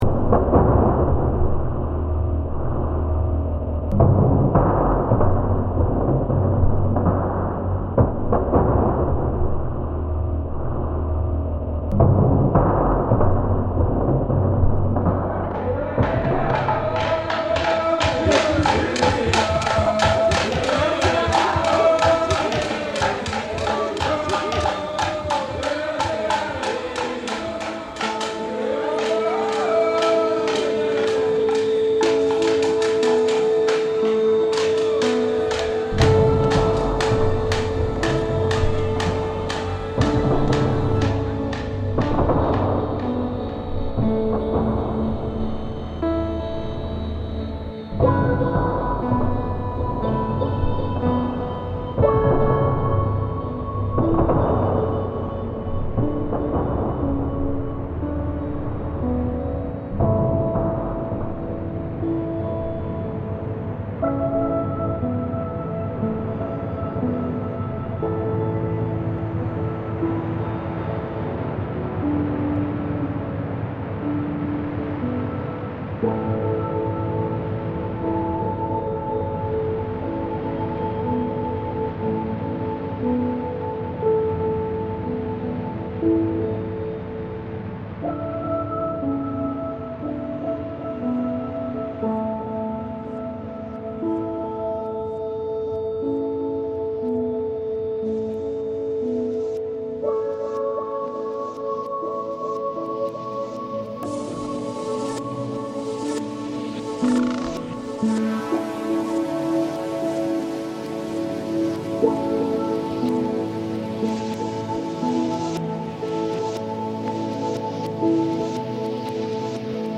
The slit drums were carved from extraordinarily large, hollowed-out trees, often standing two or three times the height of the performers. The original recording was made over half a century ago, before the islands felt the first signs of rising seas. This piece offers a brief reflection on that earlier time.
Technical notes: The drums were pitch isolated and some were pitch shifted down. The entire performance was slightly put through resonant filters and reverb that corresponded closely to the pitch and key of the singers. The drum rhythms that occur through the piece helped integrate the singing with my additional melody.